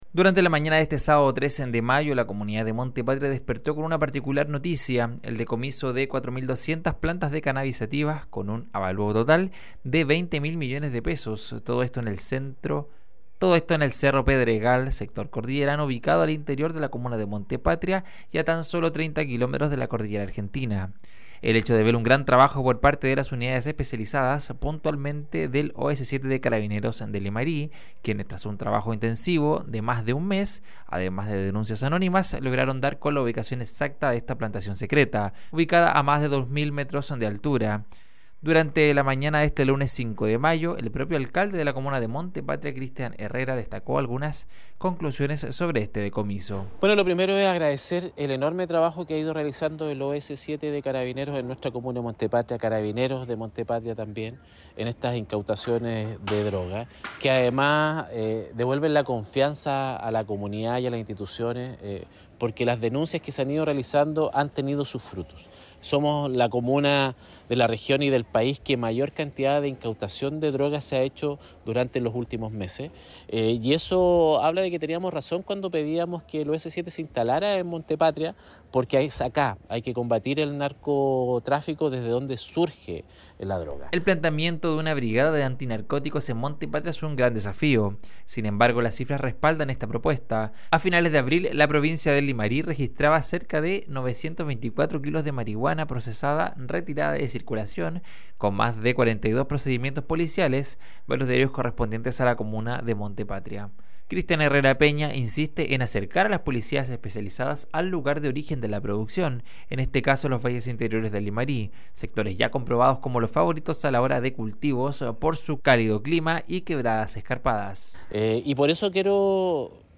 DESPACHO_INACUTACION-MARIHUANA-AVALUADA-EN-20-MIL-MILLONES.mp3